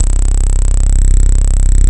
87 D50 BAS-L.wav